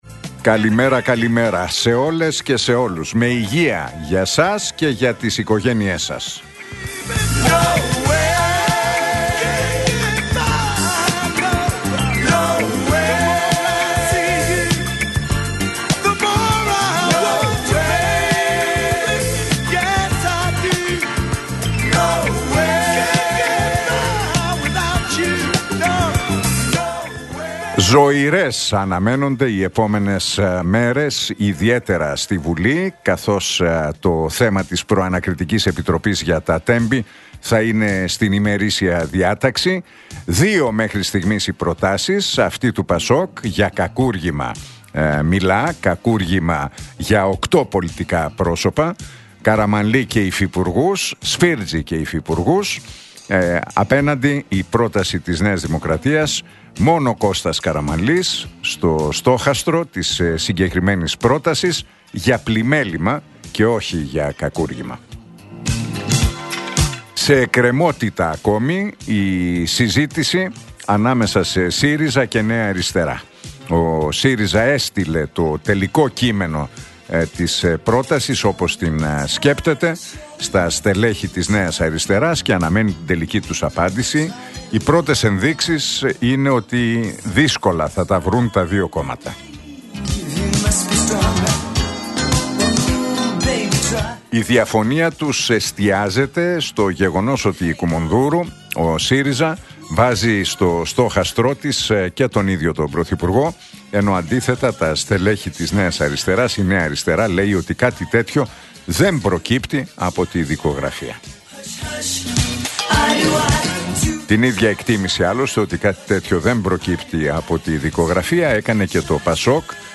Ακούστε το σχόλιο του Νίκου Χατζηνικολάου στον ραδιοφωνικό σταθμό Realfm 97,8, την Πέμπτη 29 Μαΐου 2025.